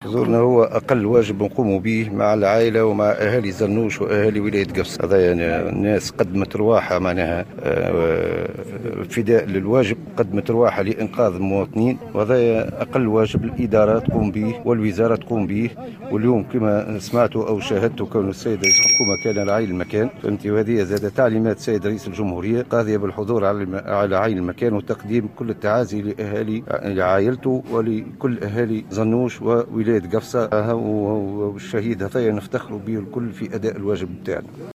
وقال وزير الداخلية لطفي براهم الذي حضر موكب الدفن رفقة وولّاة قفصة، جندوبة وقابس وعميد المحامين بتونس عامر المحرزي، في تصريح لمراسل الجوهرة اف ام أن حضوره اليوم جنازة معتمد مطماطة الجديدة محسن بن عاسي هو أقل واجب تقوم به وزارة الداخلية لتقديم العزاء لعائلة الفقيد وأهالي معتمدية زانوش باعتباره ضحّى بنفسه في سبيل انقاذ المواطنين.